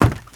STEPS Wood, Creaky, Run 02.wav